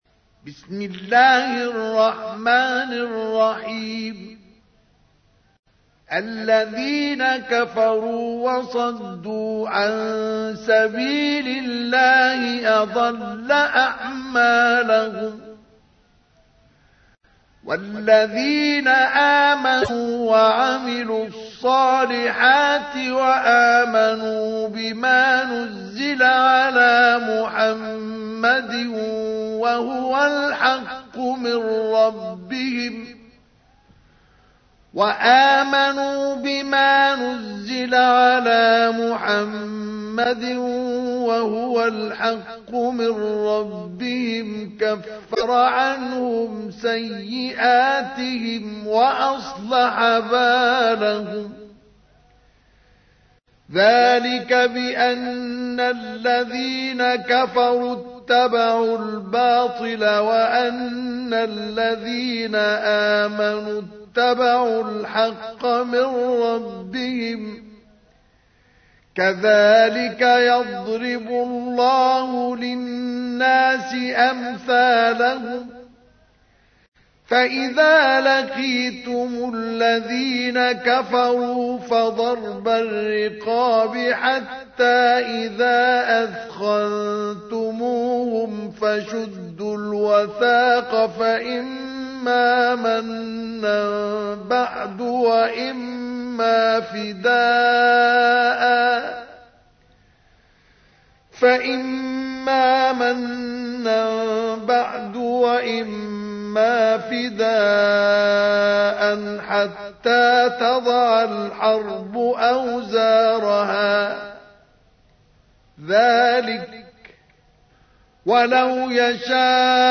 تحميل : 47. سورة محمد / القارئ مصطفى اسماعيل / القرآن الكريم / موقع يا حسين